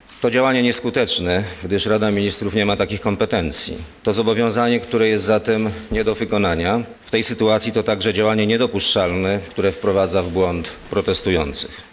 Tak o zdymisjonowaniu Łapińskiego mówi premier Miller.